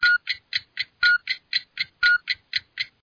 timeup_alarm.mp3